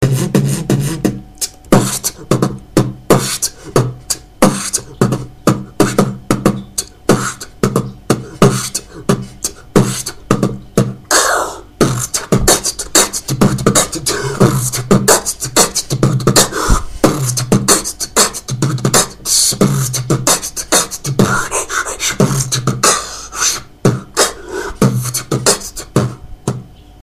Форум российского битбокс портала » Реорганизация форума - РЕСТАВРАЦИЯ » Выкладываем видео / аудио с битбоксом » Оцените)
Начал недавно, так что строго не судите))) Бит короткий)
внезавно так оборвалась запись)
скорости не хватает)
оч неплохо) скоростнее бы и мне кажется слишком слышна была отдышка там где она быть не должна (ну это мое имхо) а так очень ниче